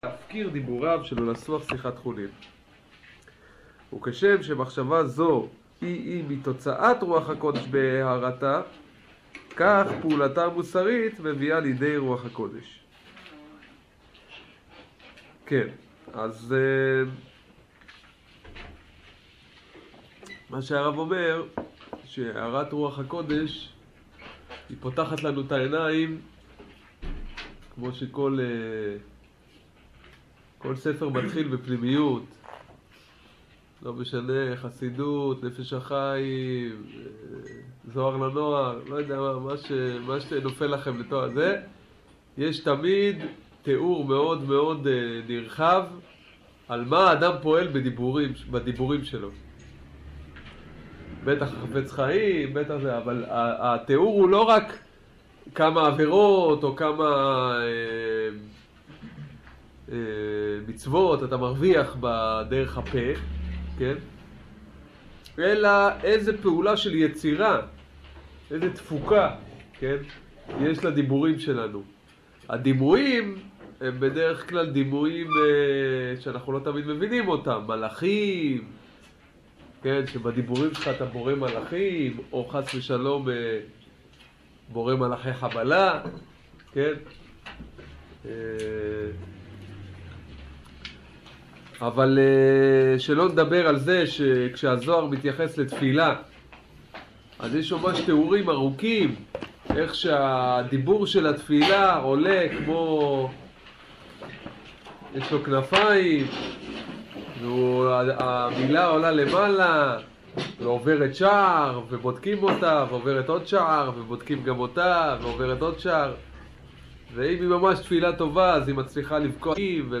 שיעור הדיבור ורוח הקודש